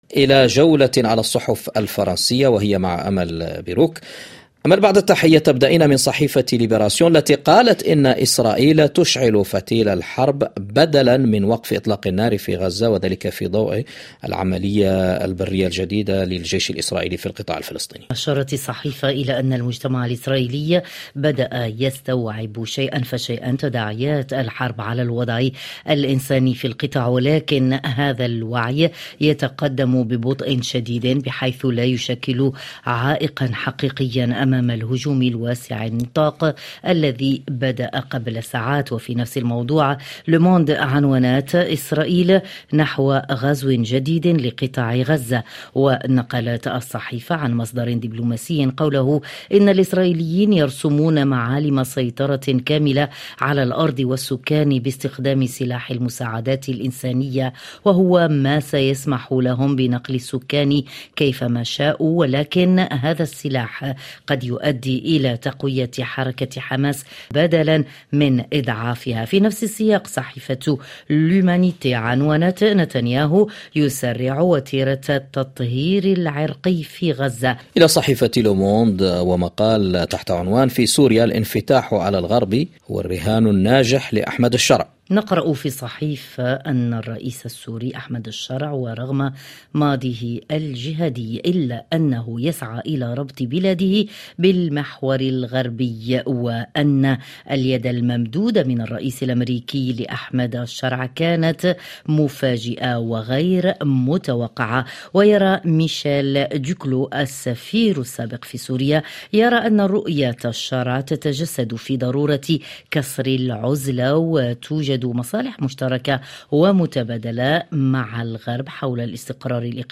ما لم تقرؤوه في صحف الصباح تستمعون إليه عبر أثير "مونت كارلو الدولية" في عرض يومي صباحي لأهم التعليقات والتحليلات لكل قضايا الساعة في فرنسا والعالم العربي والعالم وحازت على اهتمام الصحف الفرنسية.